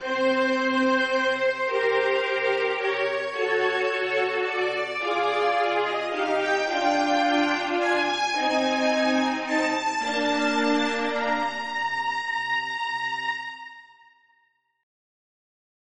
Chamamos atenção para o fato de que o sib neste caso nada mais é que uma meta temporária, obtida de um lado pela mudança subseqüente de direção melódica e de outro pela continuação do pedal de tonica.